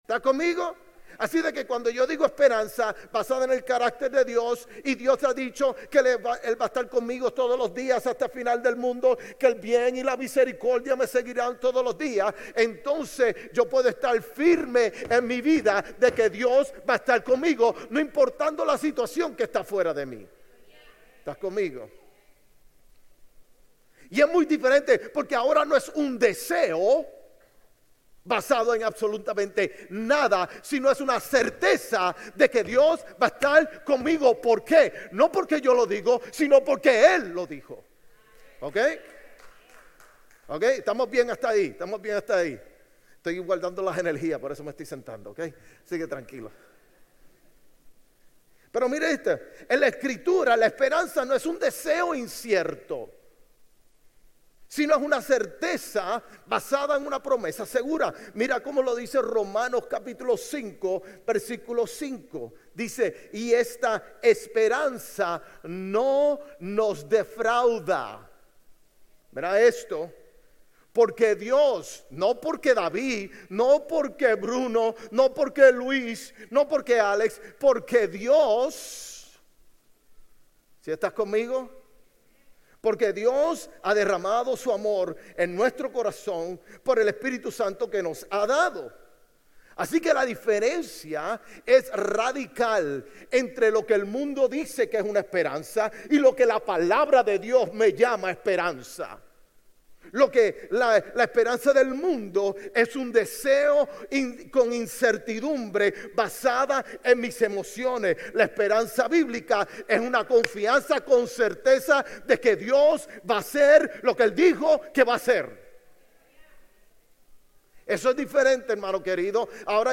Sermones Grace Español 11_16 Grace Espanol Campus Nov 17 2025 | 00:31:06 1x 00:00 / 00:31:06 Subscribe Share RSS Feed Share Link Embed